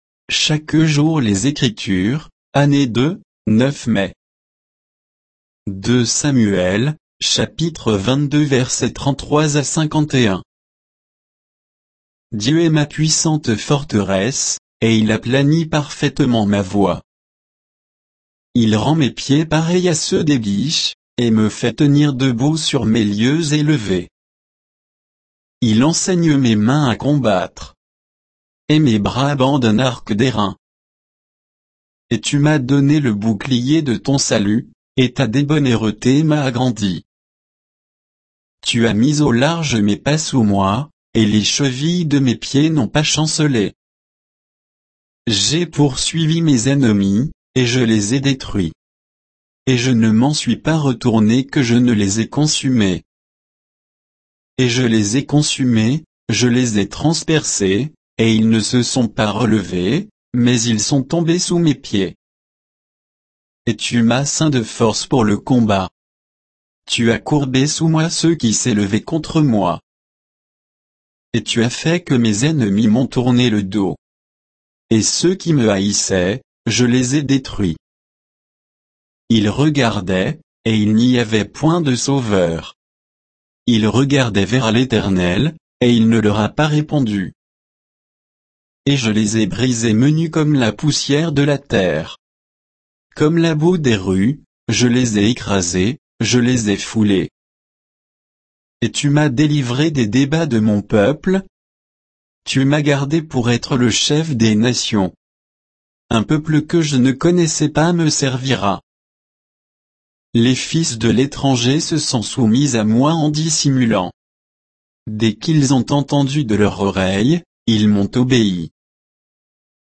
Méditation quoditienne de Chaque jour les Écritures sur 2 Samuel 22, 33 à 51